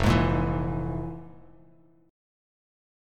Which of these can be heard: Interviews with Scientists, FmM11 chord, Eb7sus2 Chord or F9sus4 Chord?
FmM11 chord